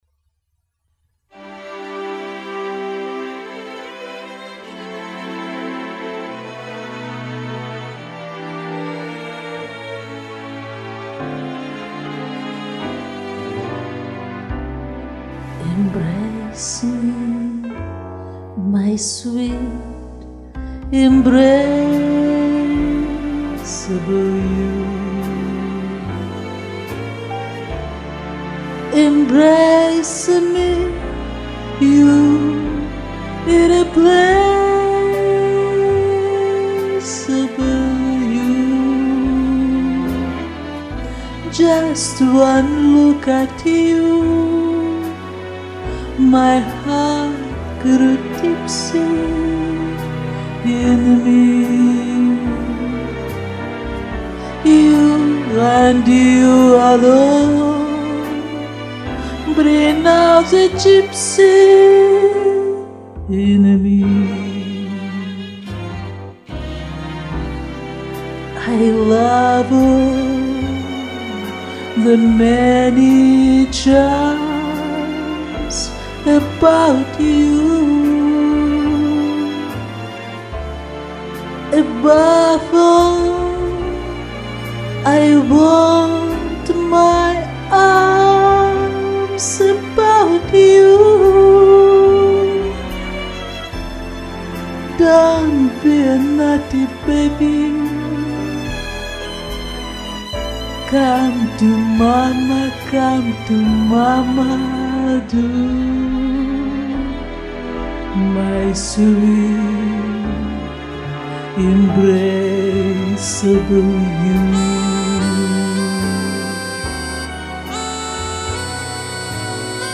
Качество звука конечно не очень ))